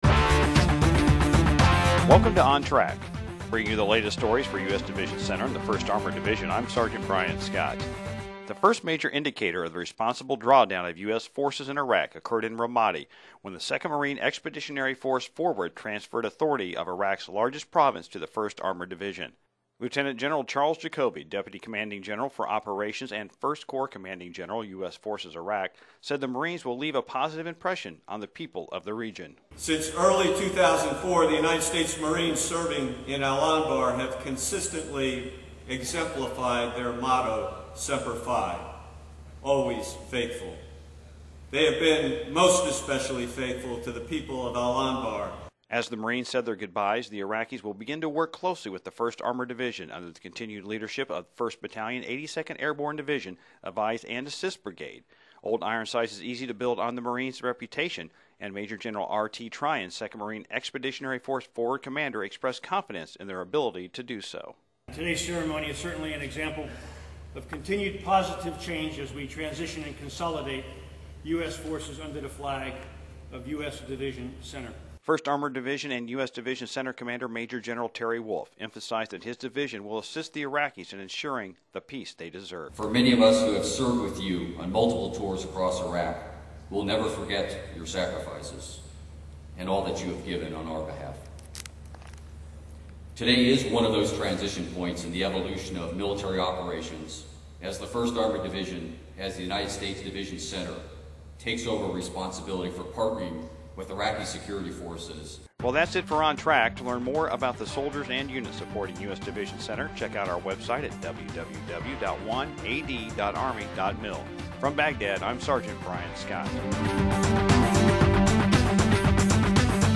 This edition features a story about the first major indicator of the responsible drawdown of U.S. forces in Iraq that occurred in Ramadi when the 2nd Marine Expeditionary Force (Forward) transferred authority of Iraq's largest province to 1st Armored Division. Interviews with Lt. Gen. Charles Jacoby, deputy commanding general for operations and I Corps Commanding General, Maj. Gen. R.T. Tryon, commander, IIMEF (Forward); Maj. Gen. Terry Wolff, commander, 1st Armored Division and U.S. Division Center.